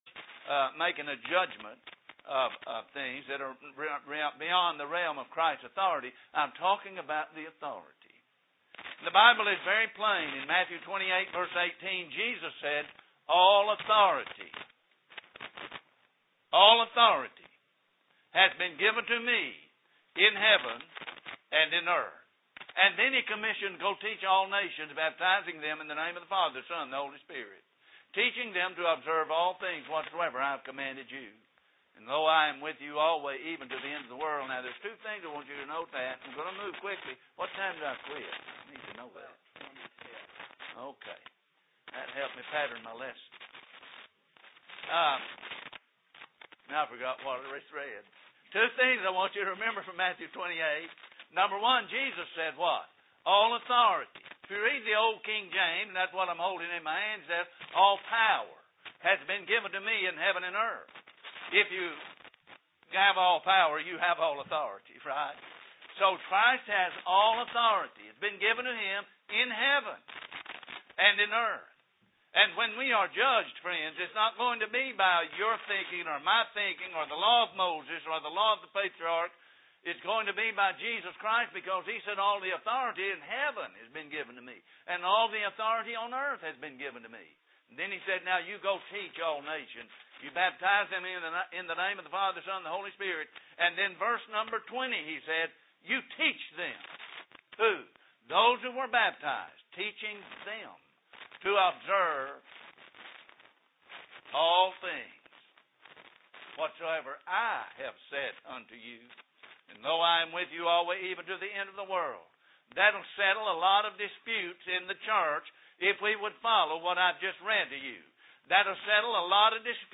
7-15-07MorningBibleClass.mp3